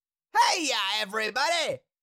Cartoon Little Monster, Voice, Heya Everybody Sound Effect Download | Gfx Sounds
Cartoon-little-monster-voice-heya-everybody.mp3